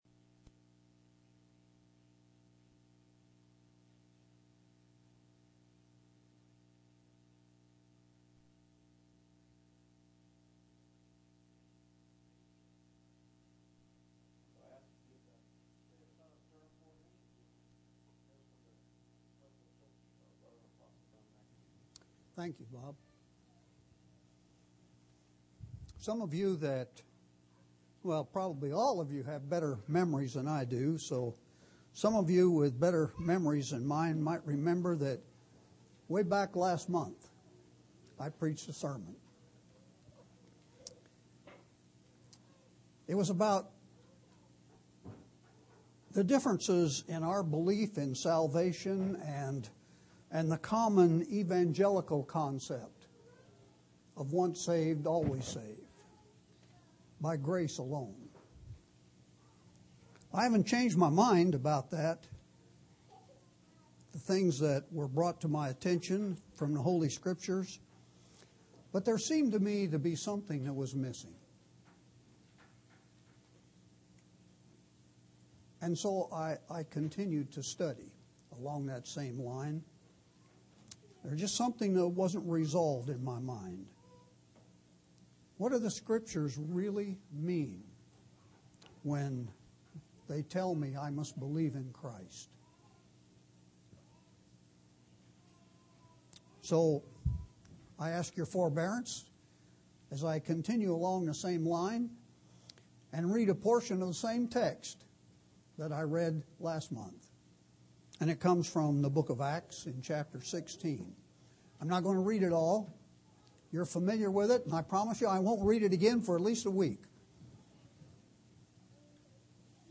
1/25/2009 Location: Phoenix Local Event